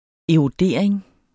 Udtale [ eʁoˈdeˀɐ̯eŋ ]